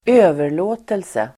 Uttal: [²'ö:ver_lå:telse]